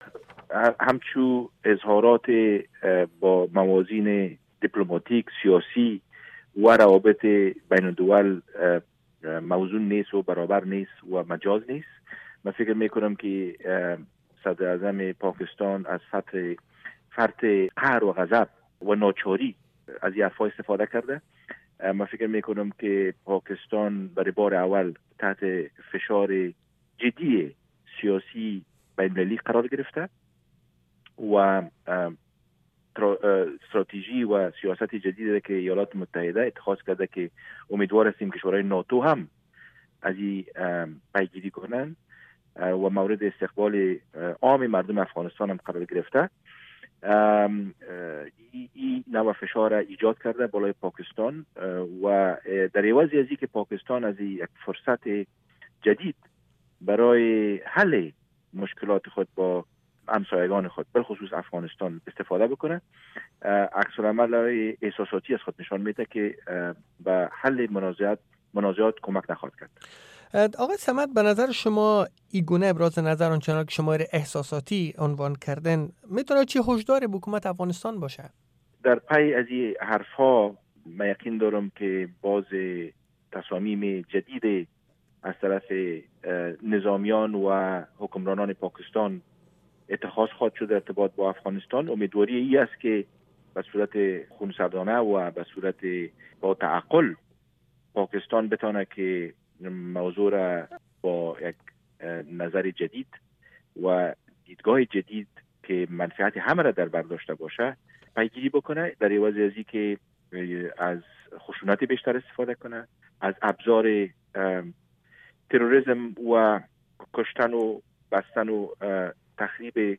مصاحبه ها
مصاحبۀ کامل آقای عمر صمد، دپلومات پیشن افغانستان، را در اینجا بشنوید